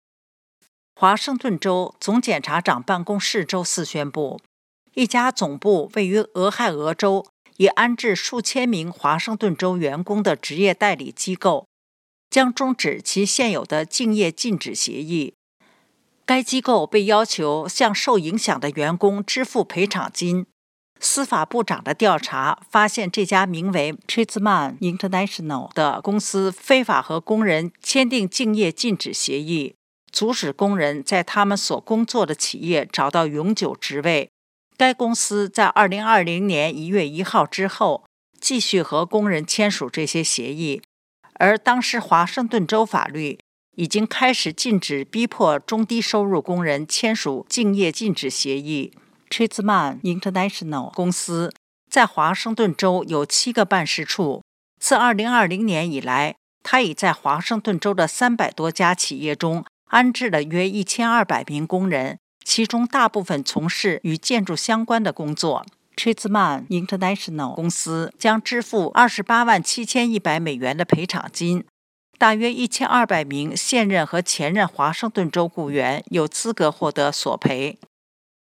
新聞廣播